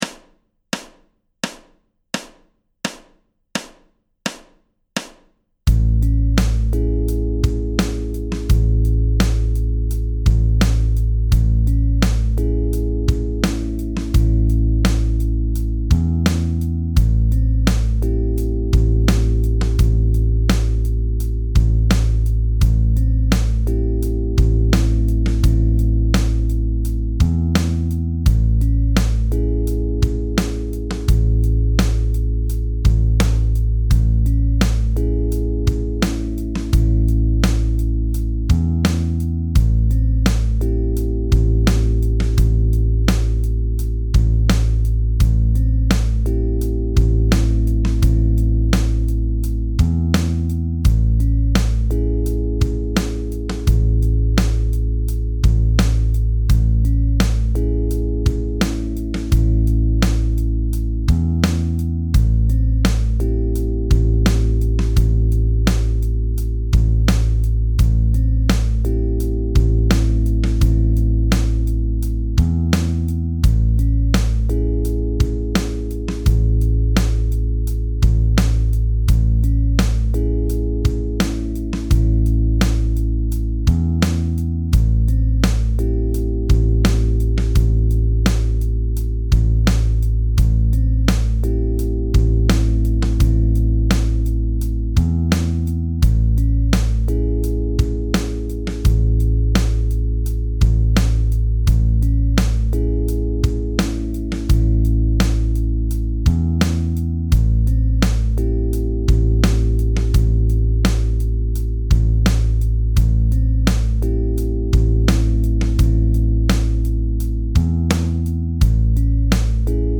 Lean how to use the classic minor pentatonic guitar scale shape to improvise over both major and minor chords.